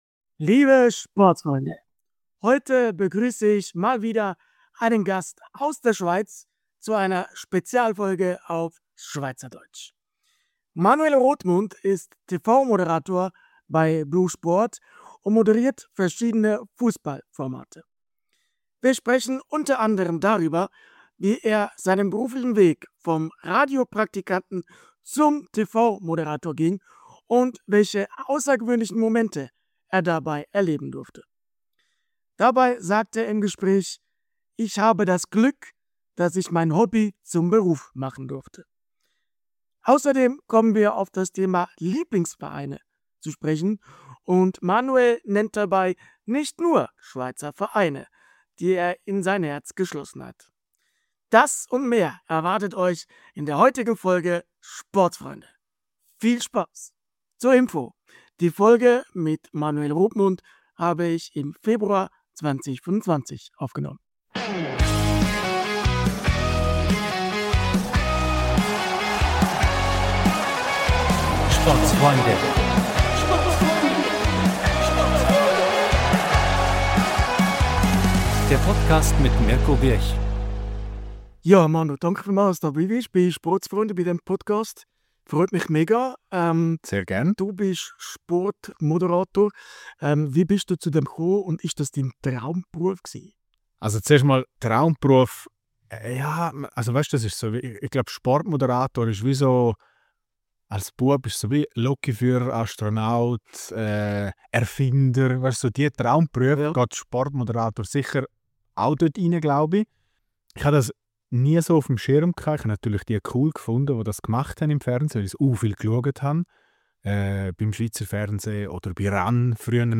Spezialfolge auf Schweizerdeutsch! ~ Mixed-Sport Podcast